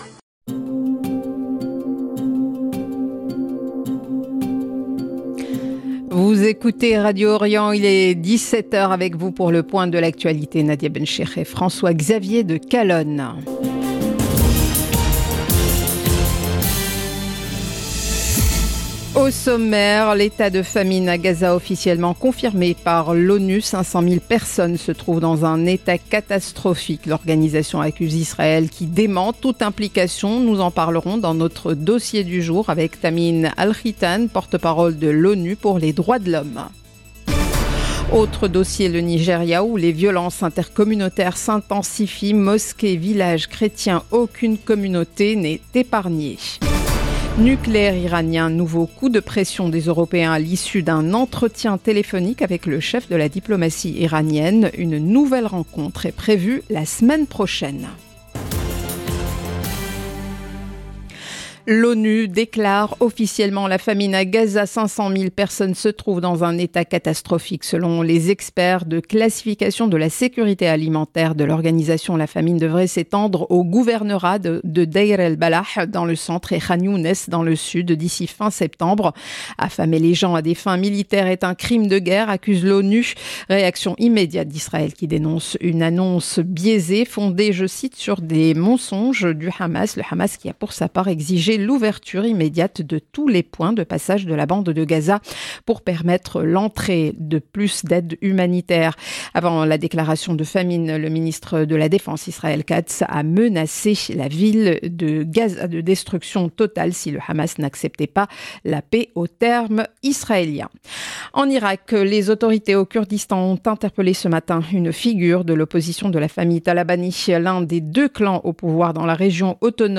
Journal de 17H du 22 août 2025